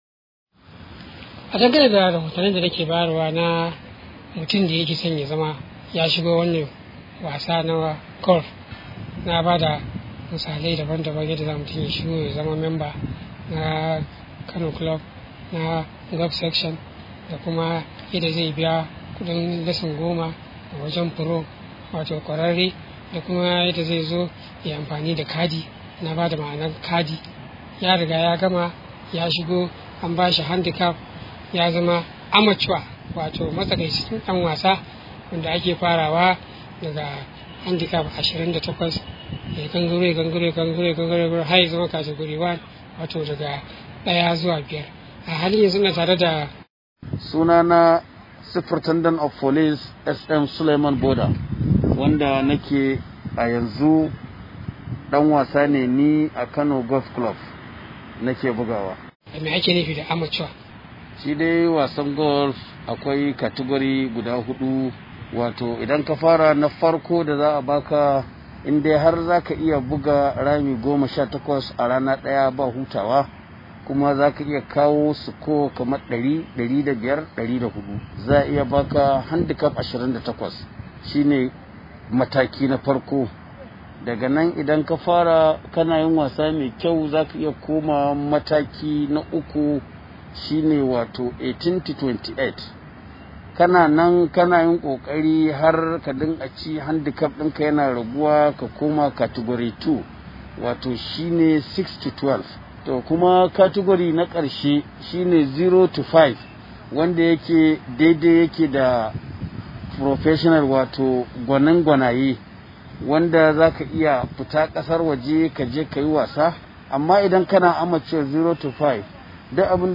ga kuma rahoton da ya hada mana.